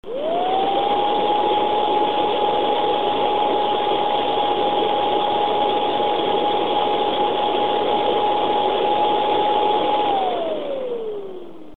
Arkua Thermal Test Results, Acoustic Sample - 6258 FrostyTech Review